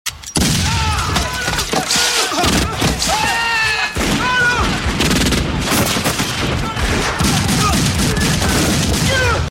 Original creative-commons licensed sounds for DJ's and music producers, recorded with high quality studio microphones.
REPETITIVE GUNFIRE AND SCREAMS.wav
REPETITIVE_GUNFIRE_AND_SCREAMS_bt1.wav